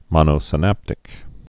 (mŏnō-sə-năptĭk)